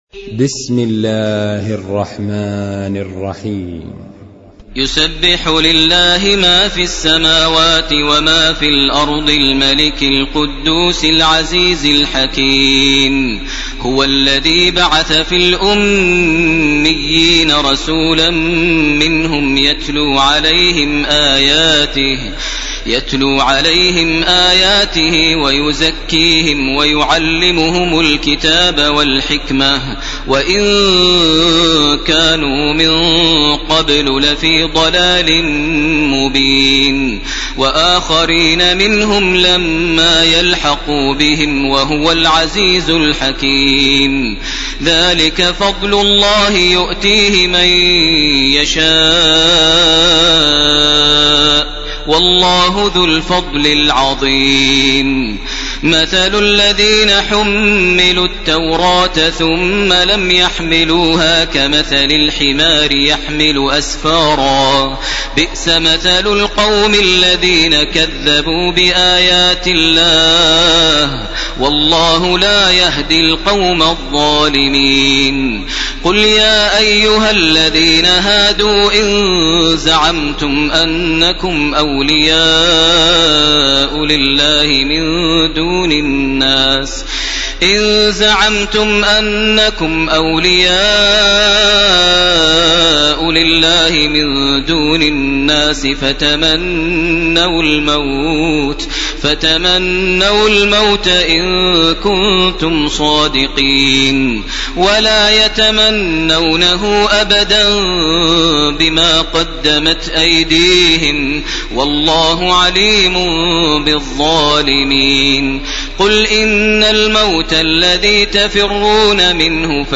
ليلة 27 من رمضان 1431هـ من سورة الجمعة إلى سورة التحريم كاملة. > تراويح ١٤٣١ > التراويح - تلاوات ماهر المعيقلي